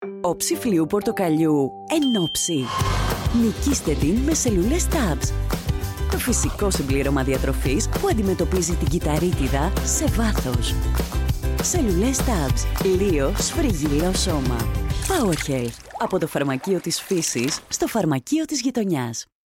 Female
30s, 40s
Bright, Confident, Cool, Corporate, Engaging, Friendly, Sarcastic, Warm
Microphone: Microtech Gefell M930Ts